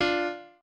piano4_16.ogg